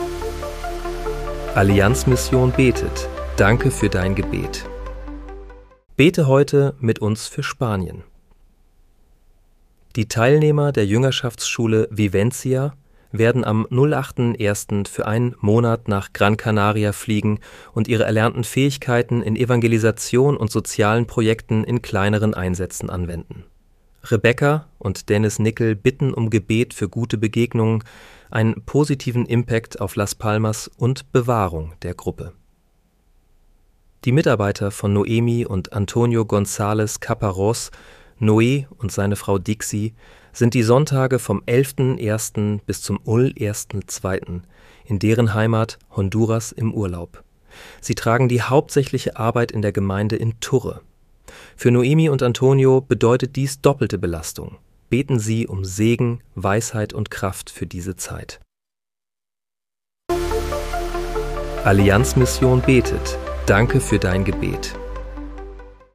Bete am 09. Januar 2026 mit uns für Spanien. (KI-generiert mit der